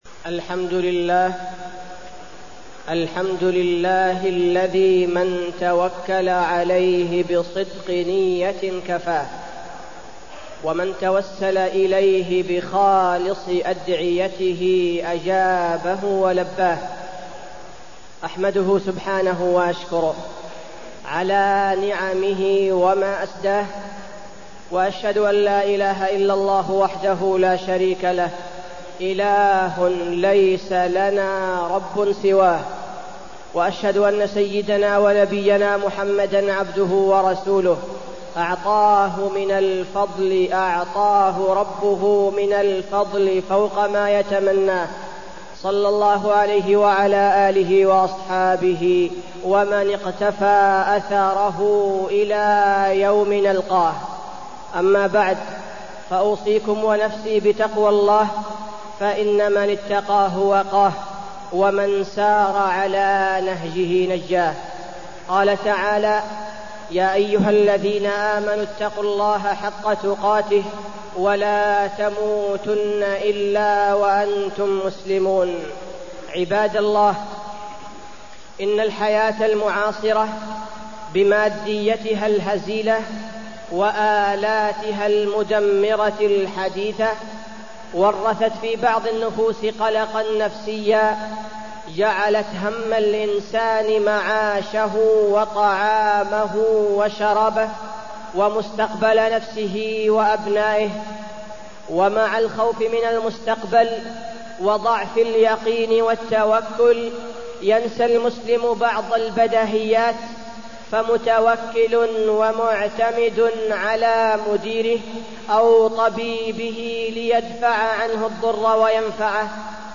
تاريخ النشر ٢٠ رجب ١٤٢٠ هـ المكان: المسجد النبوي الشيخ: فضيلة الشيخ عبدالباري الثبيتي فضيلة الشيخ عبدالباري الثبيتي التوكل